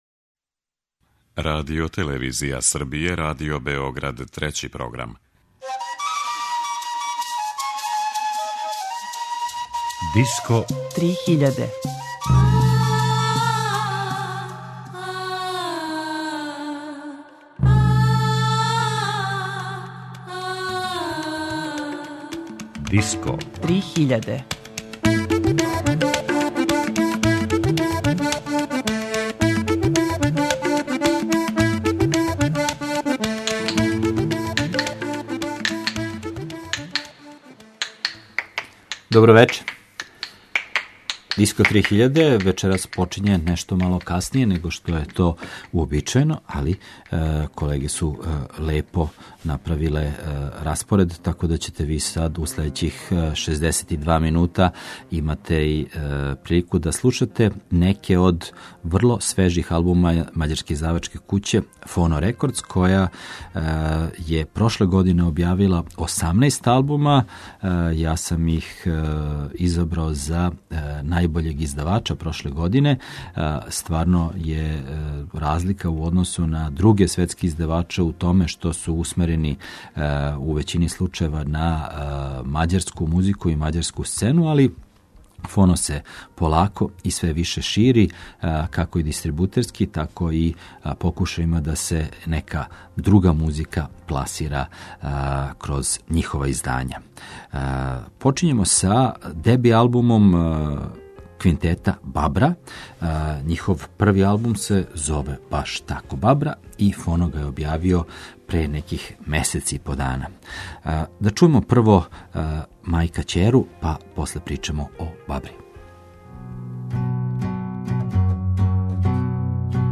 world music издавач године